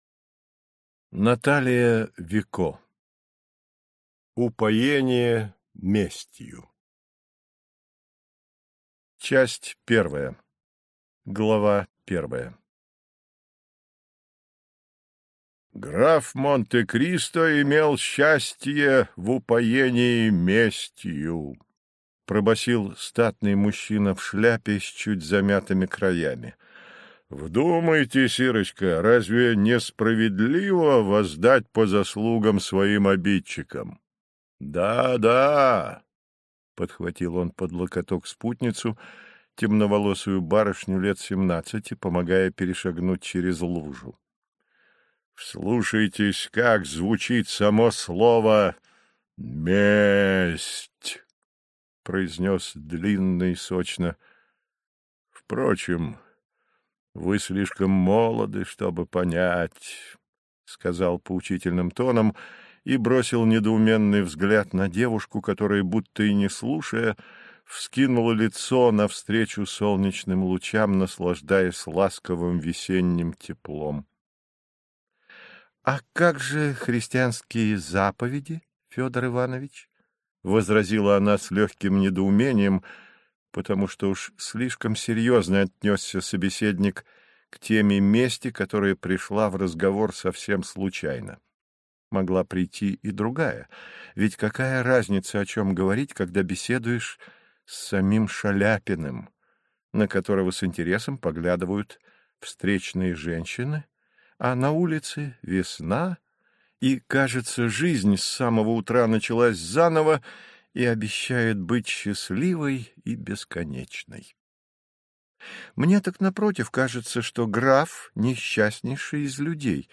Аудиокнига Упоение местью. Подлинная история графини Монте-Кристо | Библиотека аудиокниг